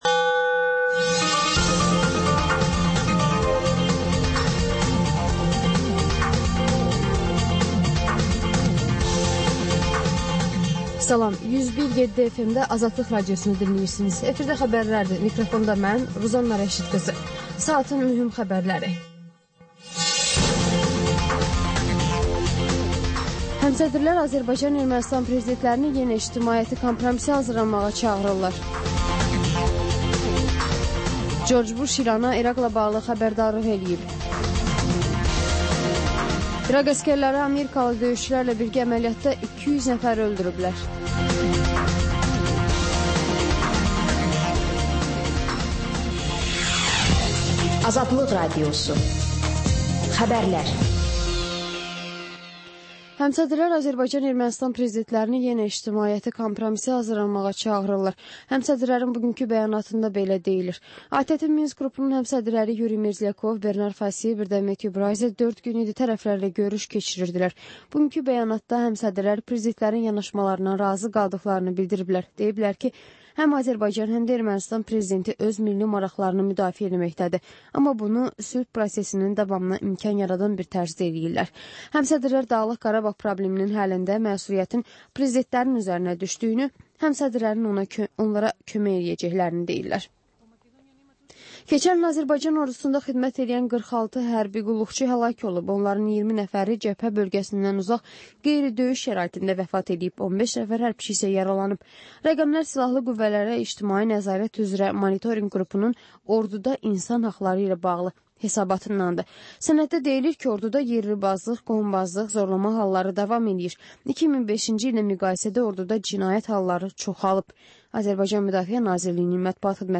Xəbərlər, reportajlar, müsahibələr. Və sonda: Azərbaycan Şəkilləri: Rayonlardan reportajlar.